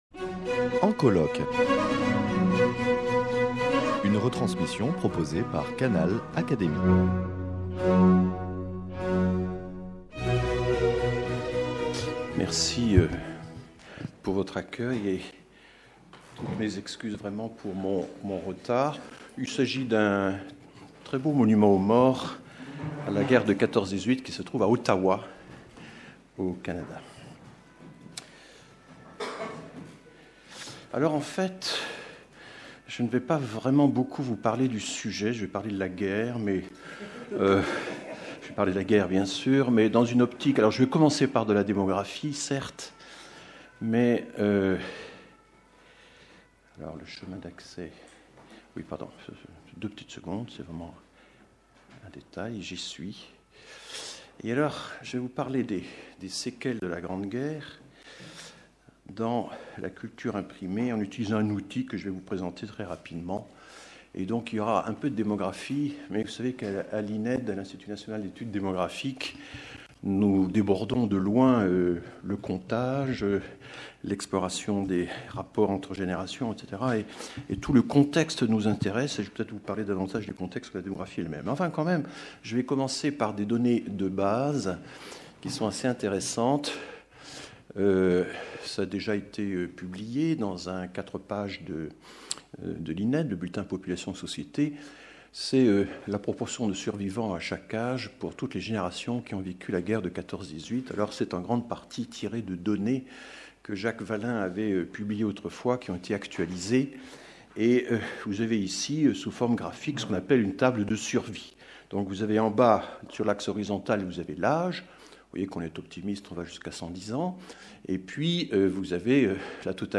Communication de François Héran, directeur de recherche à l’Ined, prononcée le 9 décembre 2015 lors des journées d’étude « Guerre et santé » organisées dans le cadre du programme de recherche « Guerre et société » soutenu par la Fondation Simone et Cino del Duca et l’Académie des sciences morales et politiques.